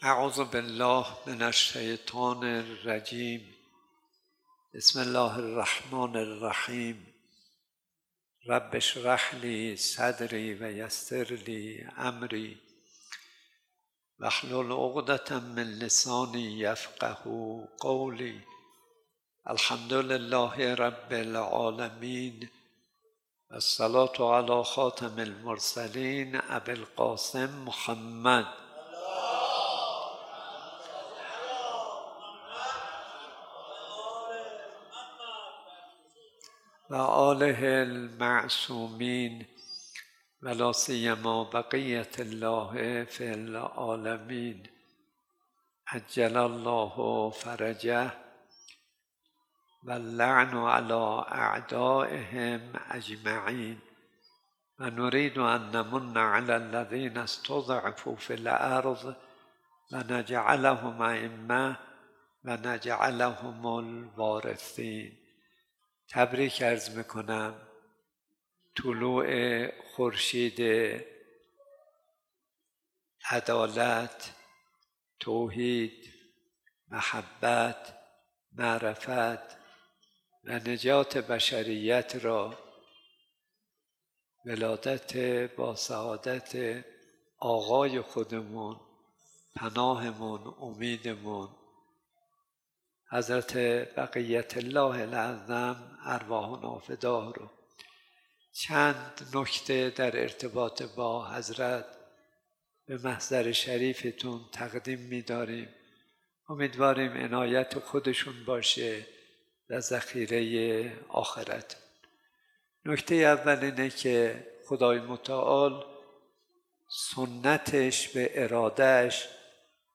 ثقلین • سخنرانی های مناسبتی
مراسم احیاء شب بیست و سوم ـ ماه مبارک رمضان ۱۴۴۵ ه.ق